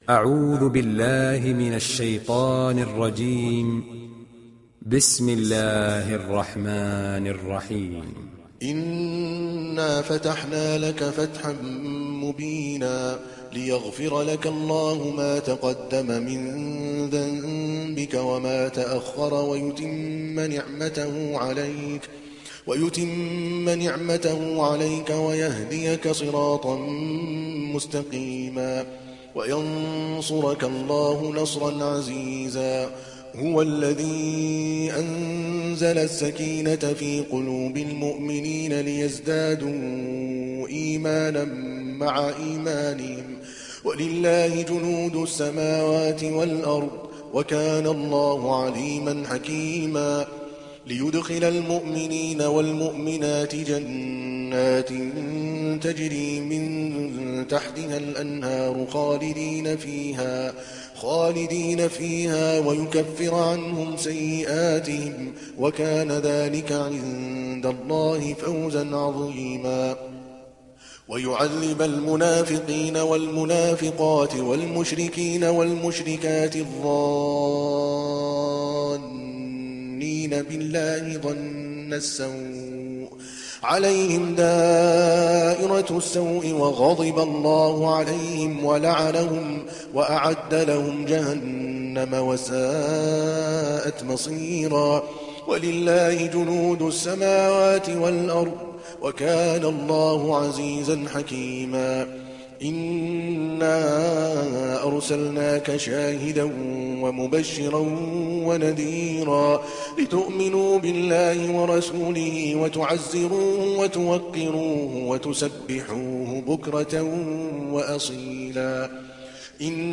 دانلود سوره الفتح mp3 عادل الكلباني روایت حفص از عاصم, قرآن را دانلود کنید و گوش کن mp3 ، لینک مستقیم کامل